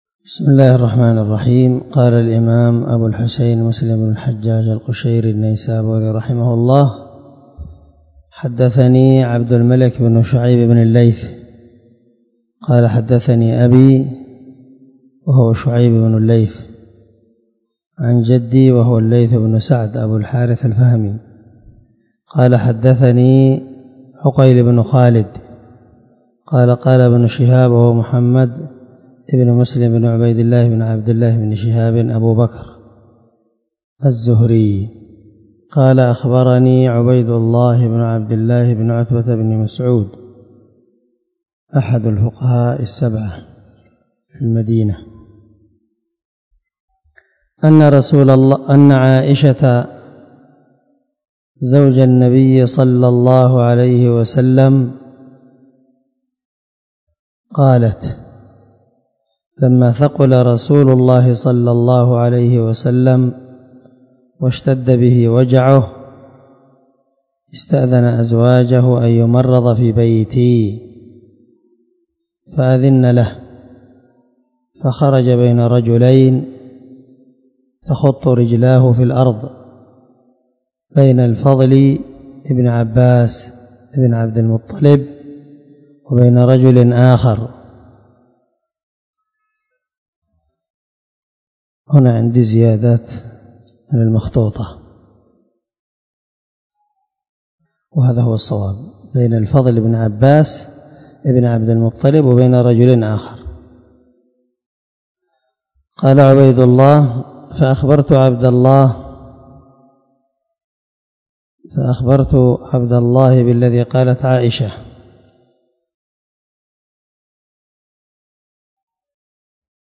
288الدرس 32 من شرح كتاب الصلاة تابع حديث رقم ( 418 ) من صحيح مسلم